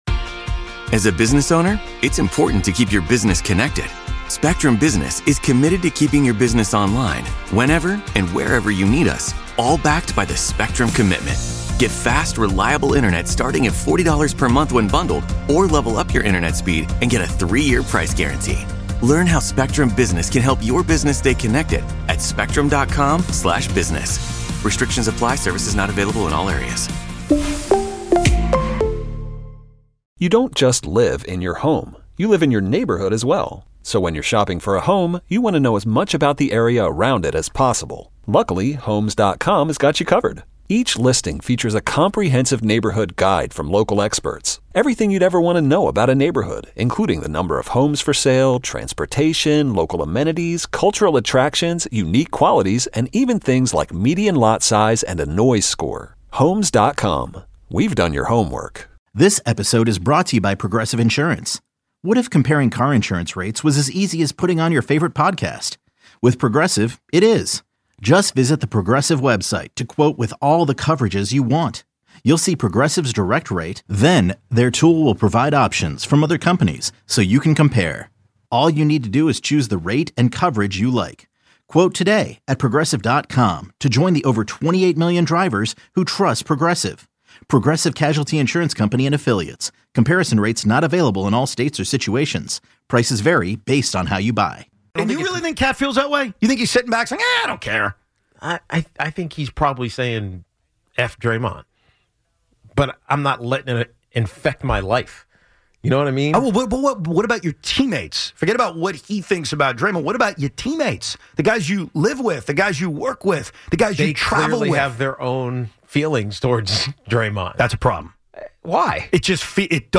Evan & Tiki Short: Tiki goes off on a caller
Evan & Tiki Short: Tiki goes off on a caller Evan & Tiki Audacy Sports 4.6 • 945 Ratings 🗓 17 March 2025 ⏱ 13 minutes 🔗 Recording | iTunes | RSS 🧾 Download transcript Summary Tiki goes off on a caller in reference to the Knicks-Draymond Green situation from over the weekend.